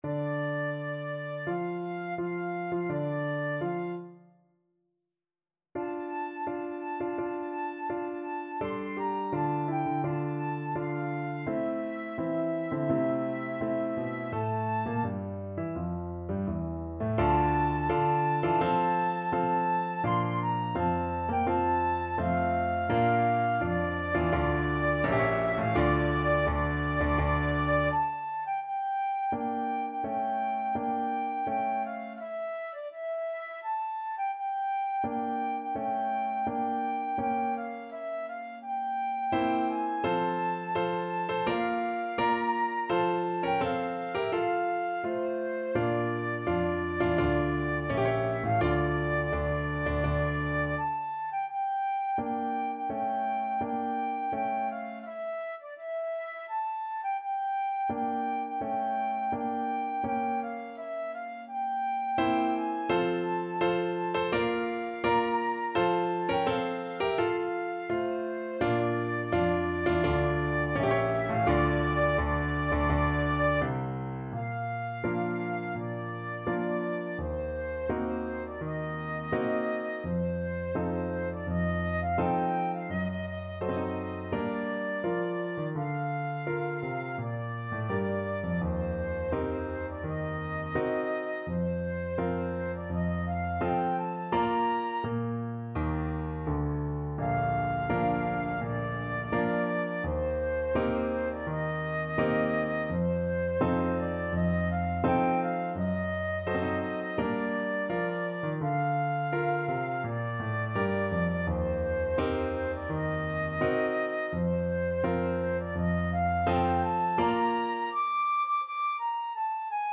Flute
Tempo di Marcia =84
D minor (Sounding Pitch) (View more D minor Music for Flute )
Classical (View more Classical Flute Music)